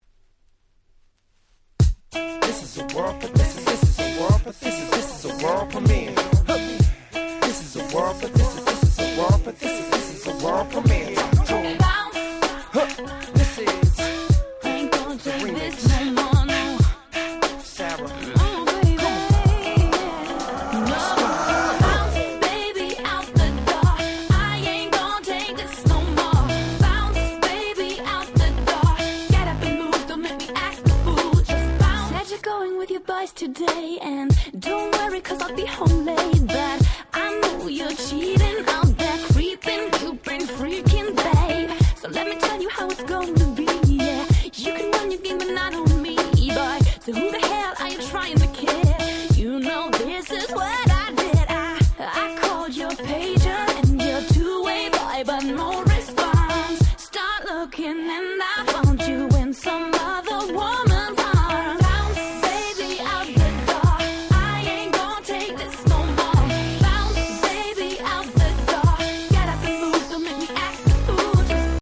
03' Nice R&B !!
フロア映えするキャッチーな良曲！